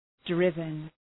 Shkrimi fonetik {‘drıvən}